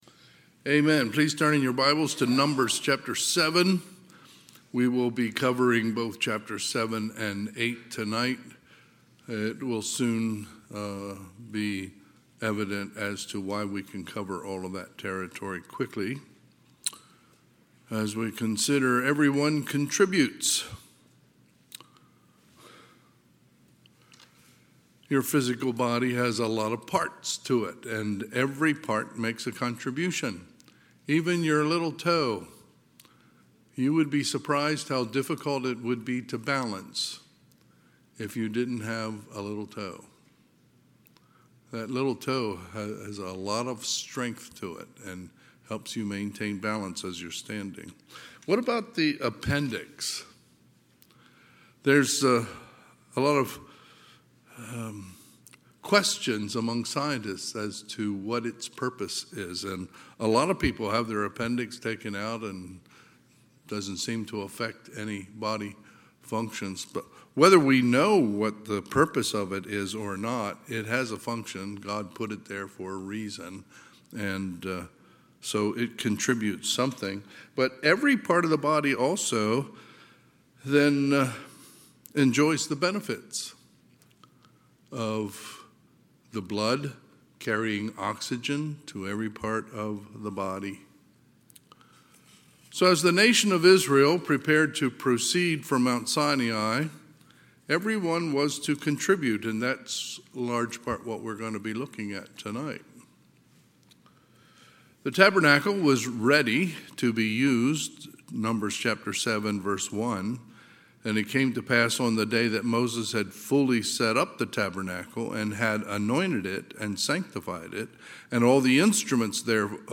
Sunday, September 21, 2025 – Sunday School